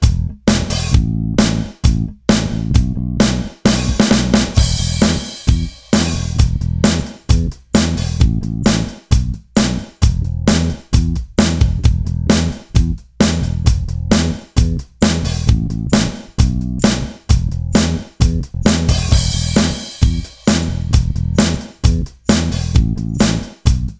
No Guitars or Harmony Pop (1980s) 3:37 Buy £1.50